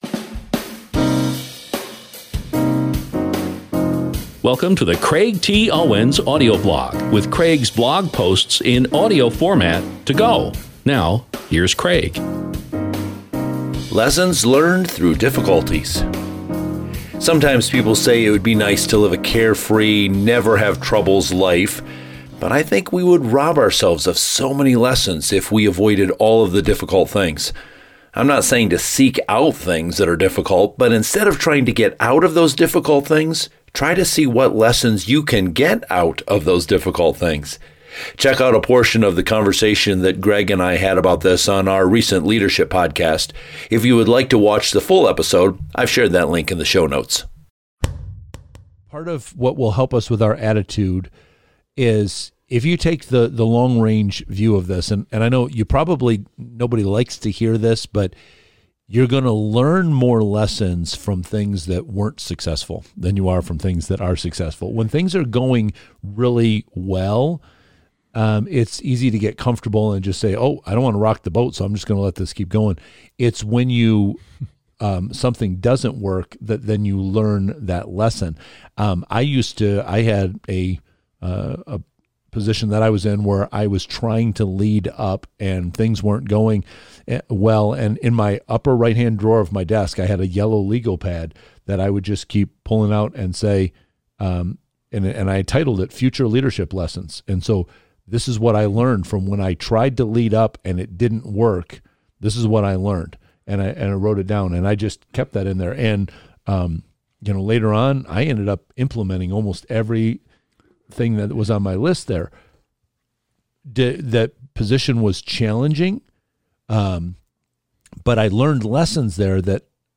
a portion of the conversation